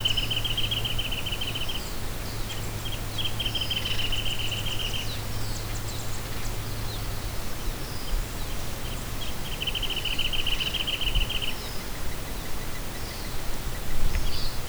Sounds of the Escondido Creek Watershed
Northern Flicker